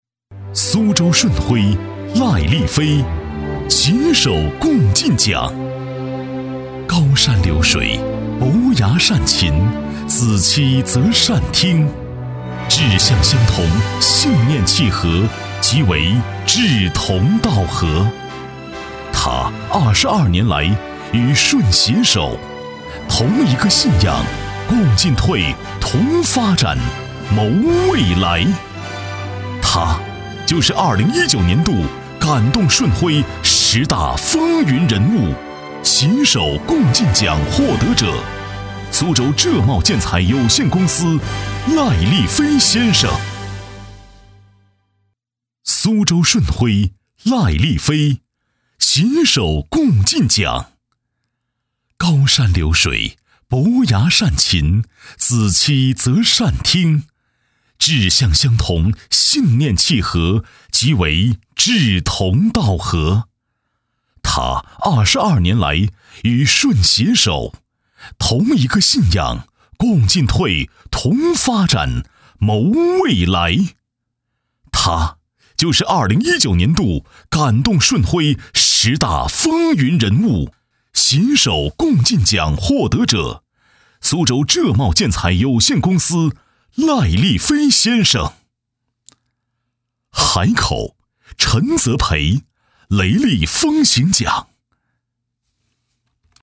142男-年会颁奖-飞乐传媒官网
毕业于中国传媒大学播音主持专业，从事配音行业数年，普通话一级甲等水平，全能型风格加之高端的品质，让他的声音得到观众和业内的认可。